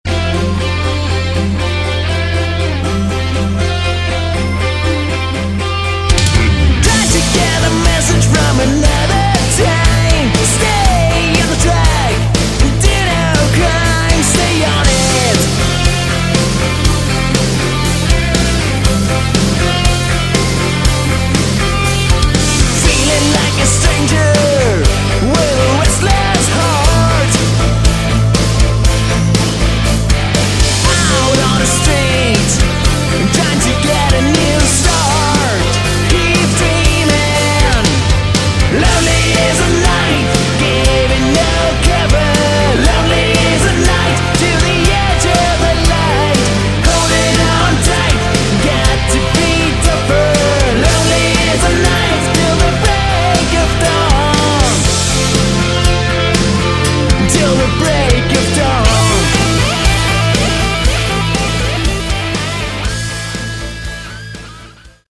Category: Hard Rock
vocals, guitar, bass and keyboards
drums